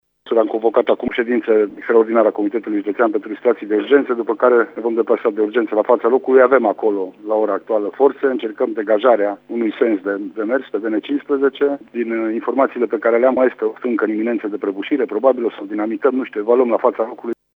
Prefectul de Mureș a convocat de urgență o ședință extraordinară a Comitetului pentru Situații de Urgență a declarat pentru RTM prefectul judeţului Mureş, Lucian Goga:
prefect-situatie.mp3